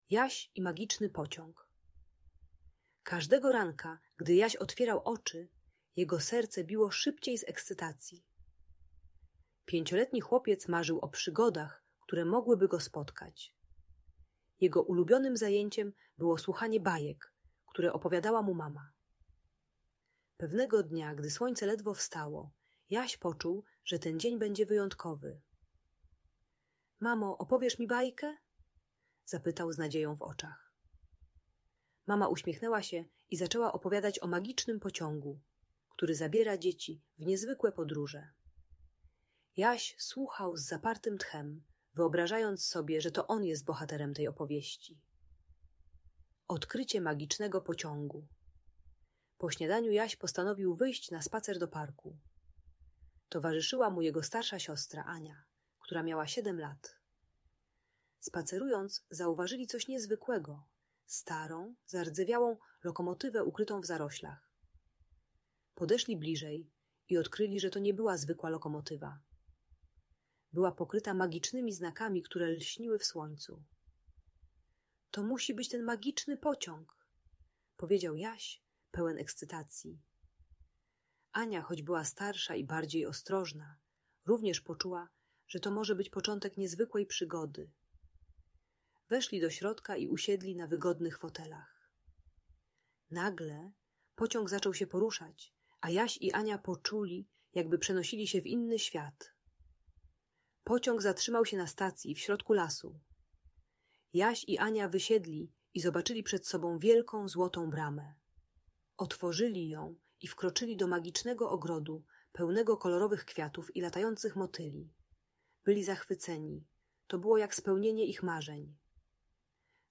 Jaś i Magiczny Pociąg - Opowieść o Przygodach - Audiobajka